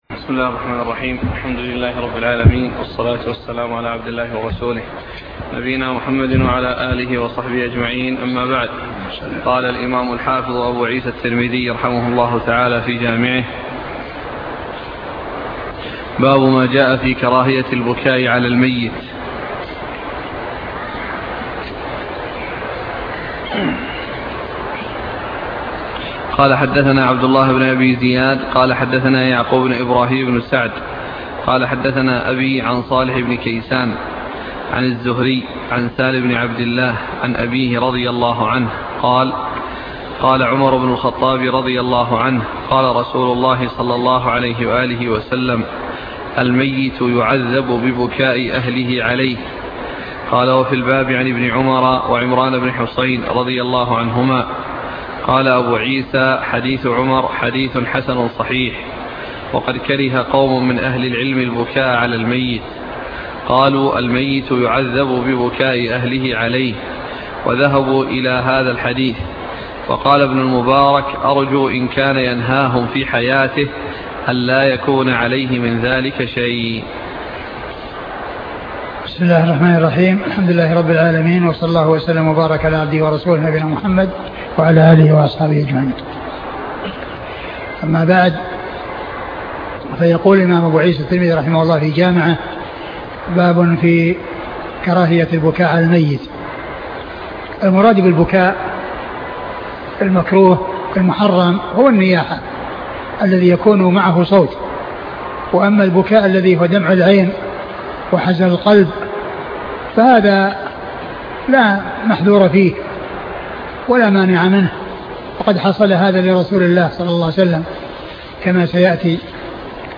سنن الترمذي شرح الشيخ عبد المحسن بن حمد العباد الدرس 121